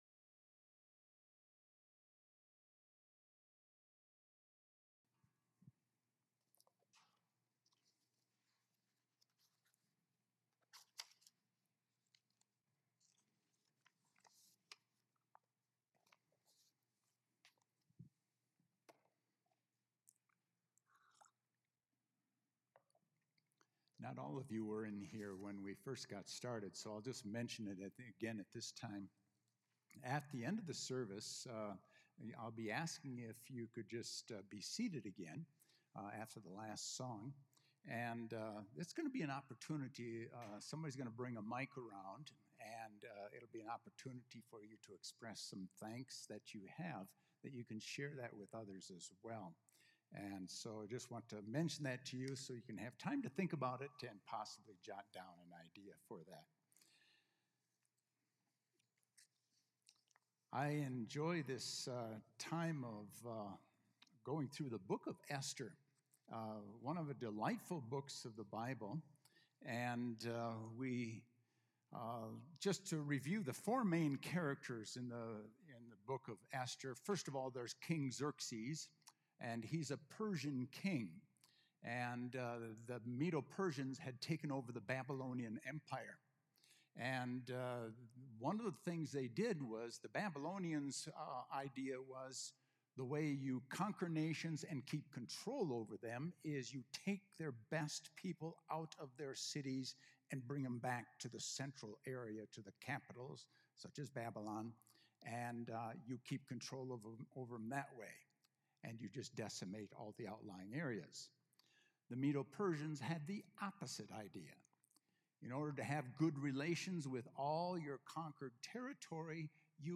A message from the series "Book of Esther."